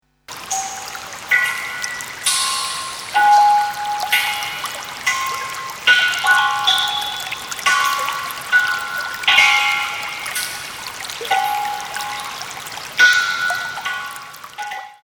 川のせせらぎ、鳥のさえずり、波の音、虫の声、
気持ちの良い自然音と、カリンバ、サヌカイトなどの楽器、
水琴窟の響きなどをミックス。